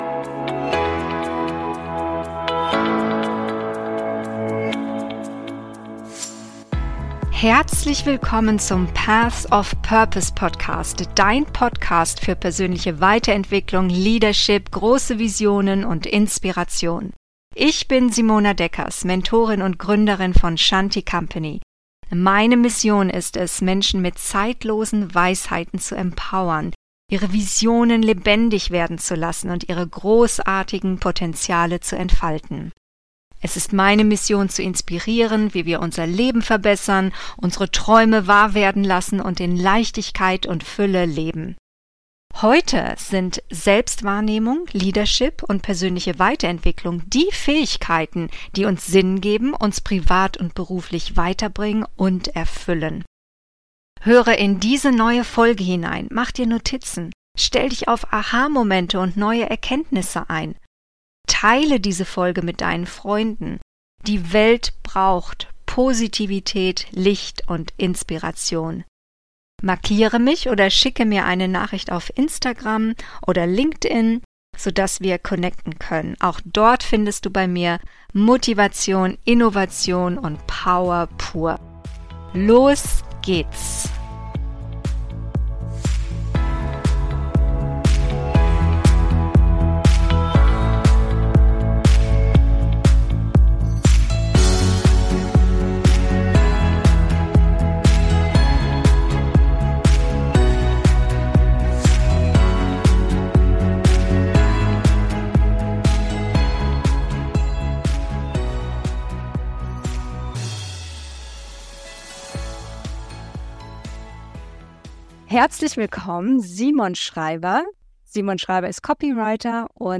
Copywriting: Einfluss nehmen durch Worte - Interview